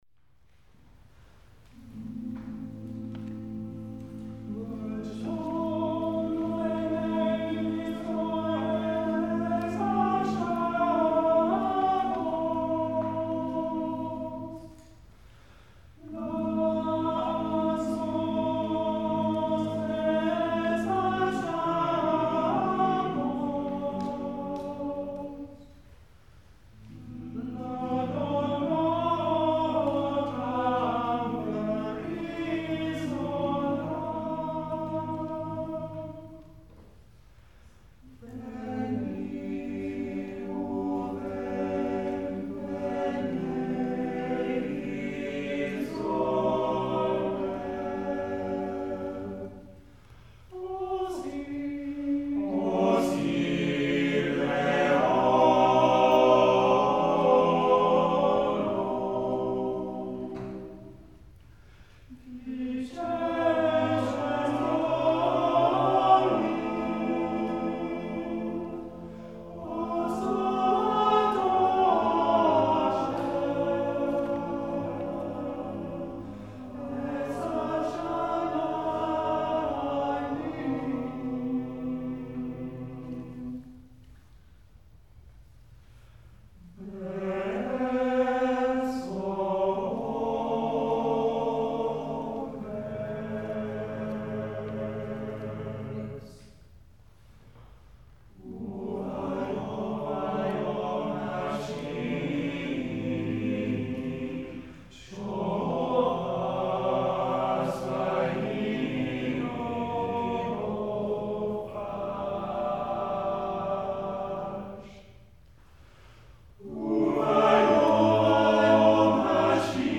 Part of a concert titled ‘Songs from a forgotten world’: Jewish choral music, old and new